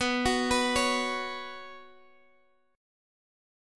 Bsus2 chord